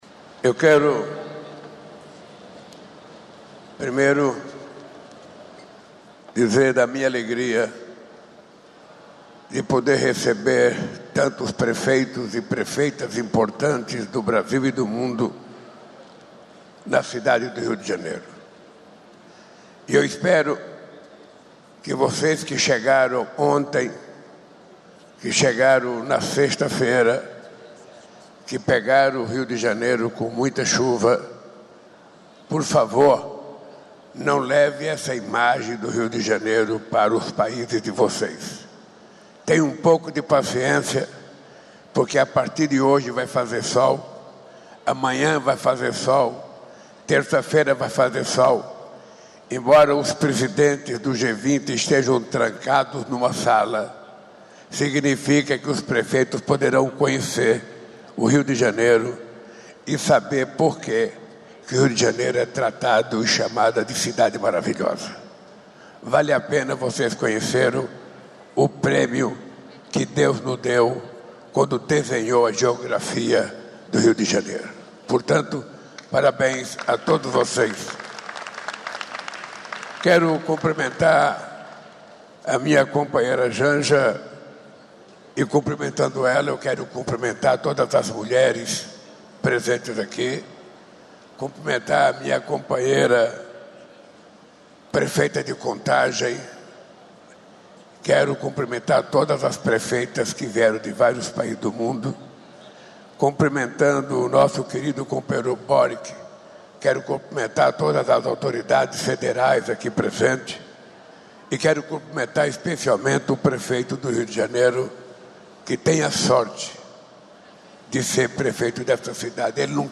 Confira a íntegra da fala do Presidente Lula na Plenária da Urban 20, fórum dos prefeitos do G20, neste domingo (17), no Rio de Janeiro.